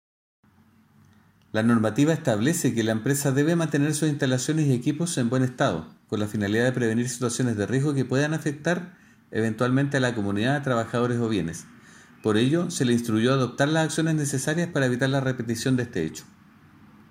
Audio: Manuel Cartagena, Director Regional de SEC Bio Bío